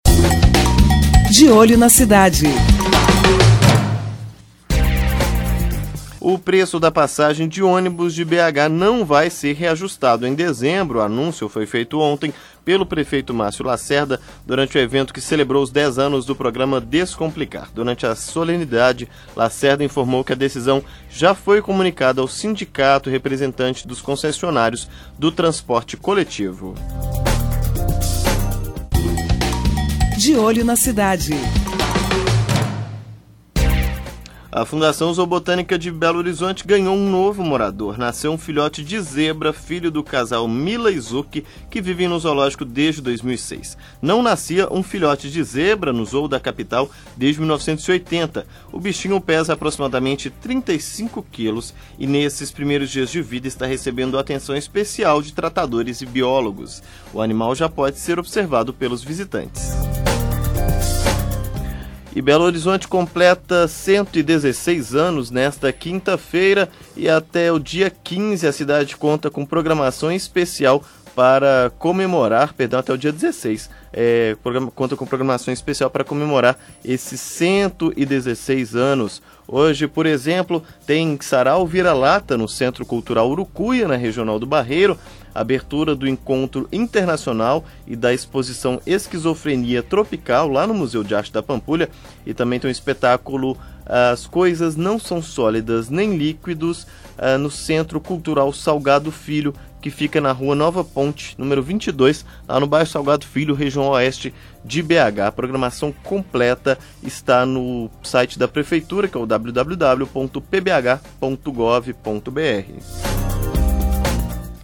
Jornalismo